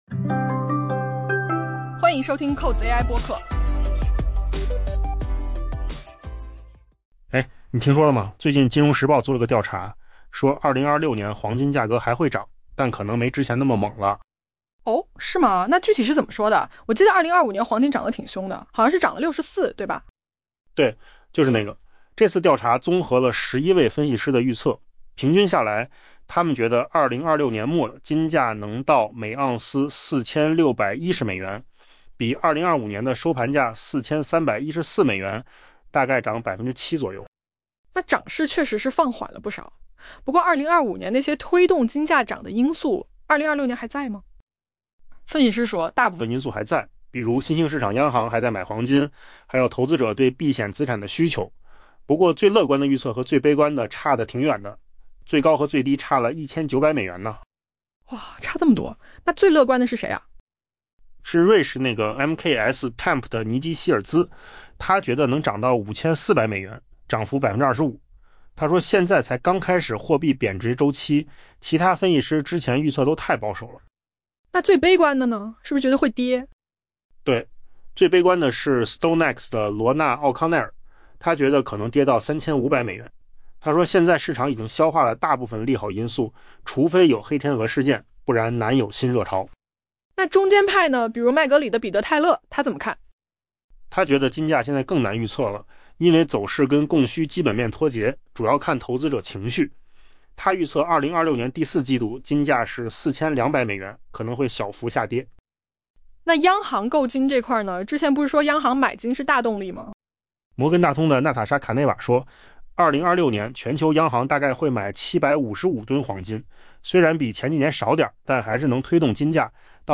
AI 播客：换个方式听新闻 下载 mp3 音频由扣子空间生成 英国 《金融时报》 的一项调查显示，2026 年黄金价格将延续其历史性涨势并刷新高点，不过分析师预计，在经历了一年的惊人涨幅后，黄金的上涨步伐将会放缓。